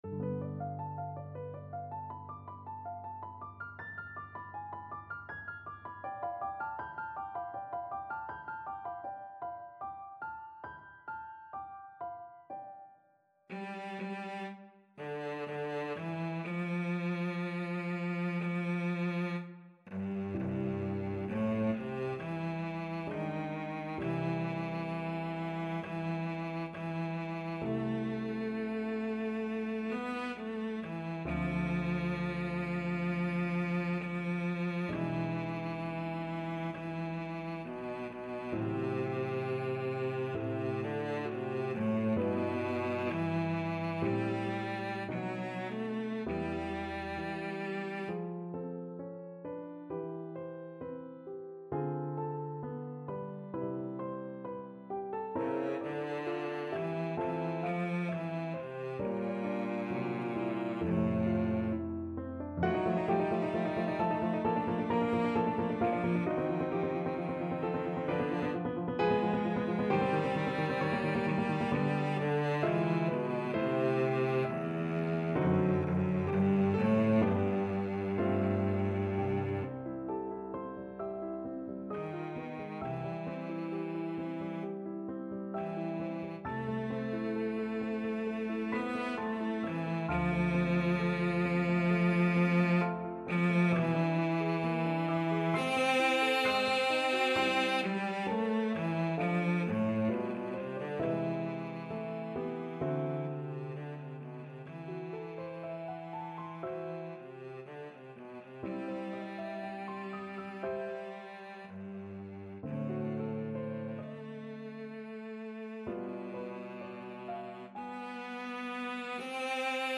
Classical Cilea, Francesco Io son l'umile ancella from Adriana Lecouvreur Cello version
Cello
E major (Sounding Pitch) (View more E major Music for Cello )
4/4 (View more 4/4 Music)
= 80 Lentamente
Classical (View more Classical Cello Music)